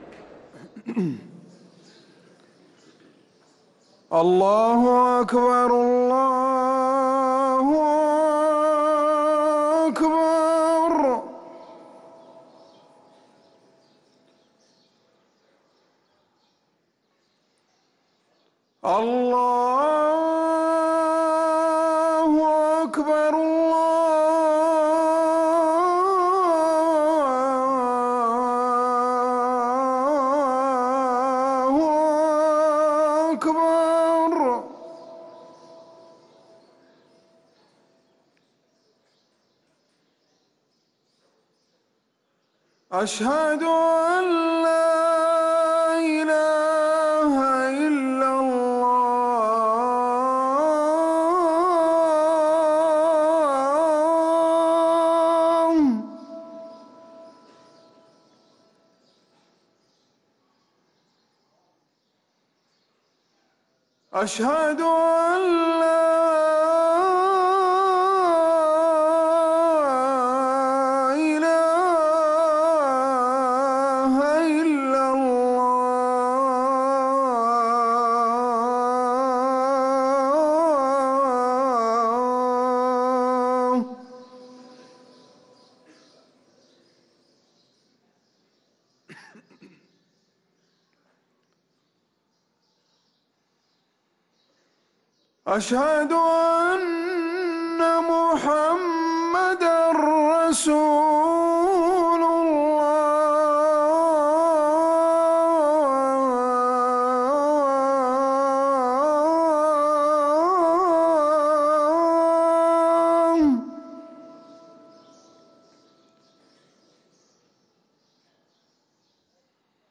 أذان الفجر للمؤذن عمر سنبل الأحد 29 جمادى الآخرة 1444هـ > ١٤٤٤ 🕌 > ركن الأذان 🕌 > المزيد - تلاوات الحرمين